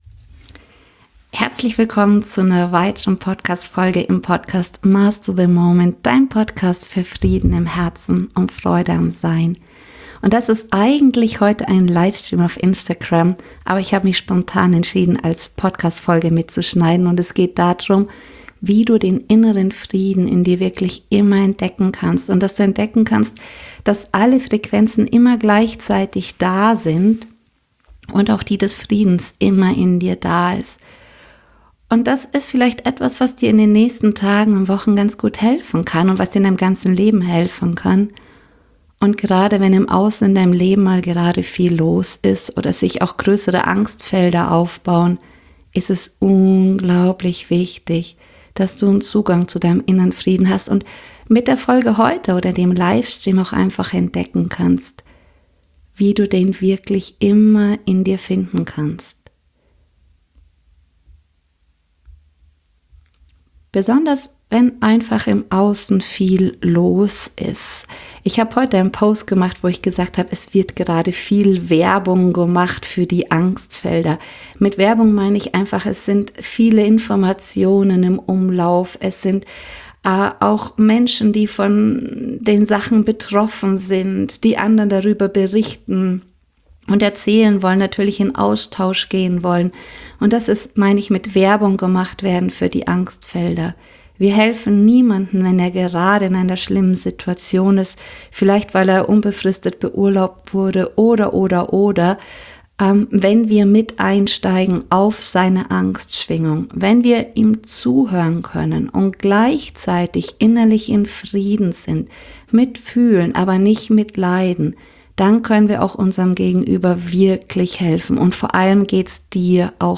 Innerer Frieden macht nicht lethargisch, inneren Frieden macht lebendig, macht Dich frei und wirklich unabhängig, lässt dich klarer Entscheidungen Treffen, anderen hilfreicher zur Seite stehen und kraftvoll durchs leben zu gehen. Diese Folge ist ein Livemitschnitt eines Instagram Livestreams.